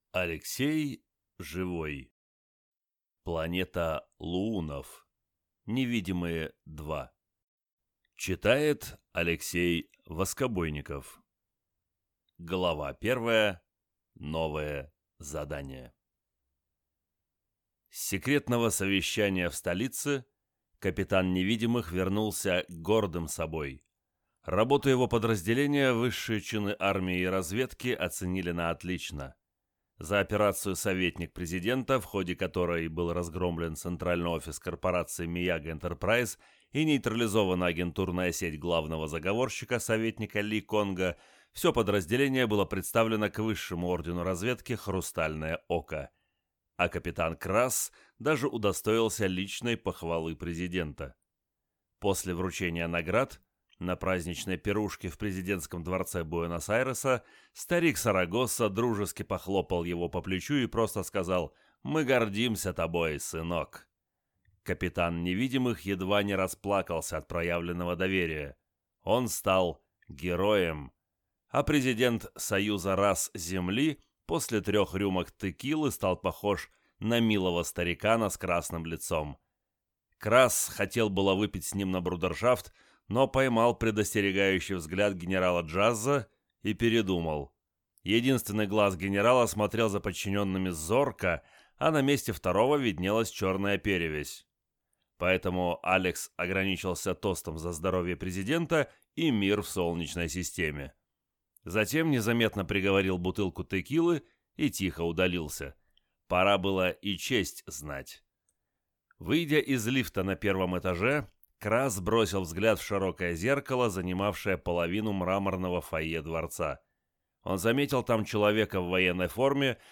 Аудиокнига Планета луунов | Библиотека аудиокниг